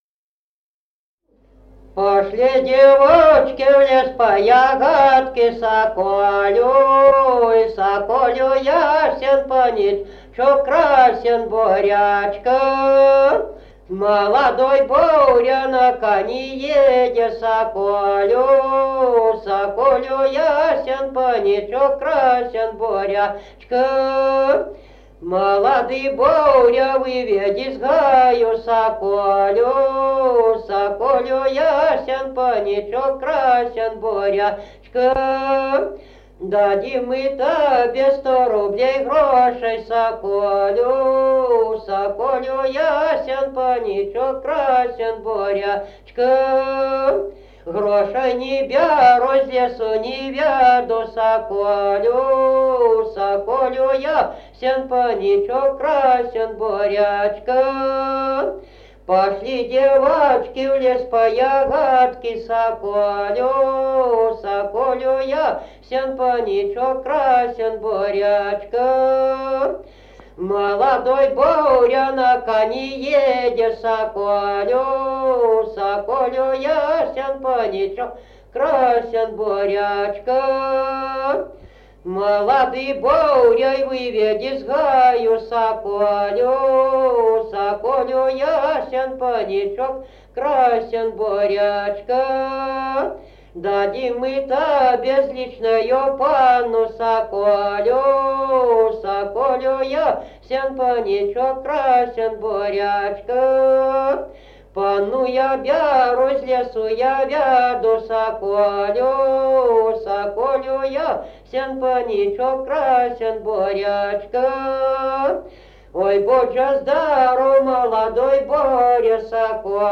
Народные песни Стародубского района «Пошли девочки», новогодняя щедровная.
1953 г., д. Камень.